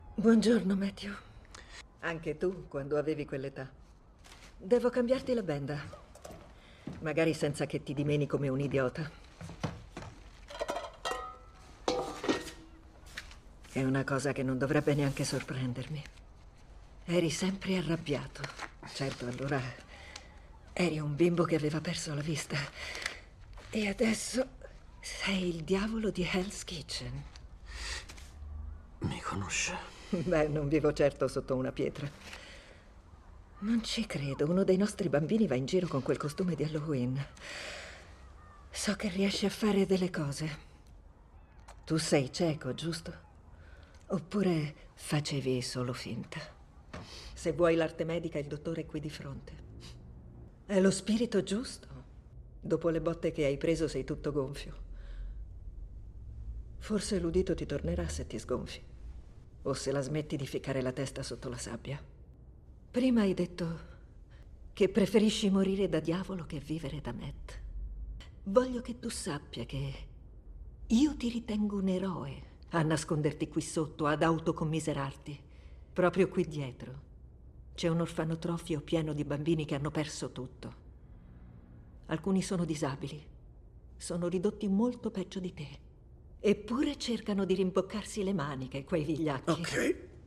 FILM CINEMA